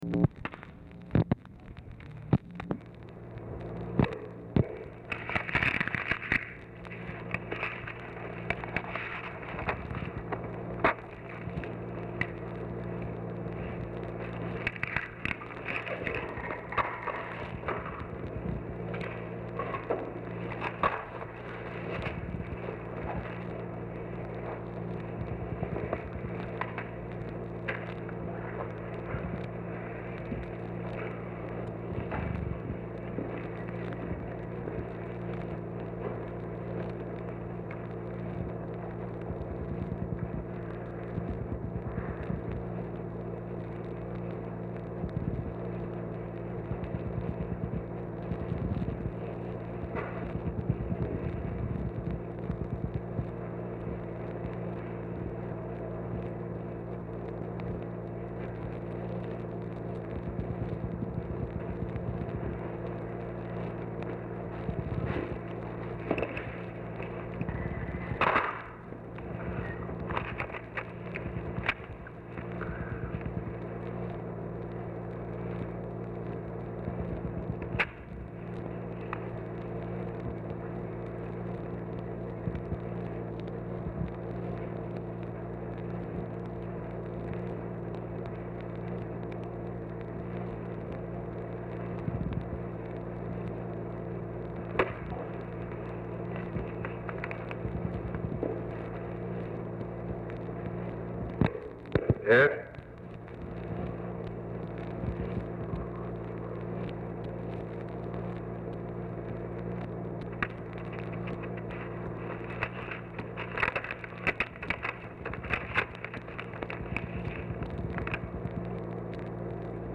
CONVERSATION PRECEDED BY 2:50 OFFICE NOISE
Format Dictation belt
Location Of Speaker 1 Oval Office or unknown location
Specific Item Type Telephone conversation